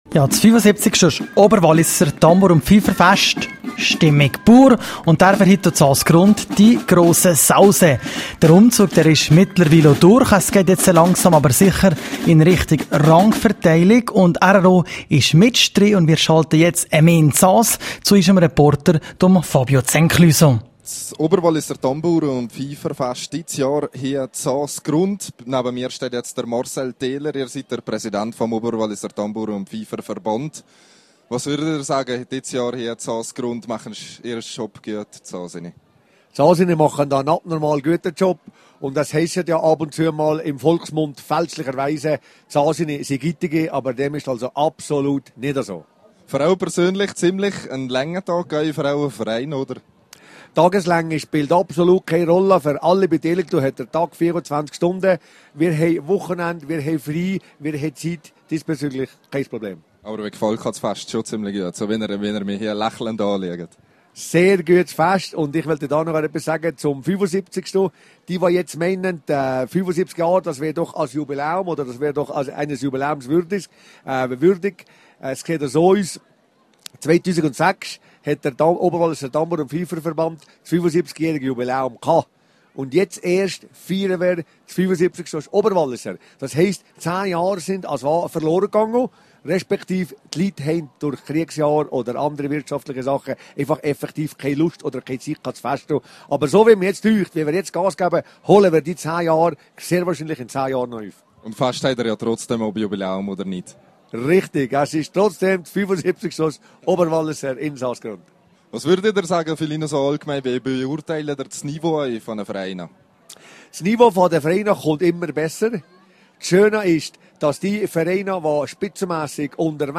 Am Wochenende trafen sich in Saas-Grund 26 Oberwalliser Tambouren- und Pfeifervereine zum Fest. Grosser Publikumsaufmarsch und beste Stimmung waren garantiert.
(Quelle: rro) rro am Oberwalliser Tambouren- und Pfeiferfest (Quelle: rro)